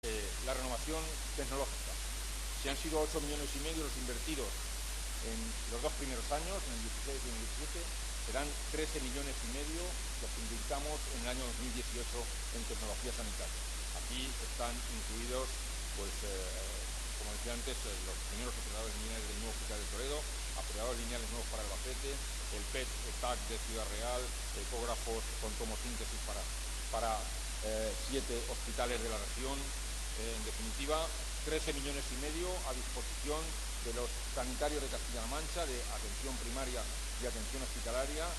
corte_2_consejero_sanidad.mp3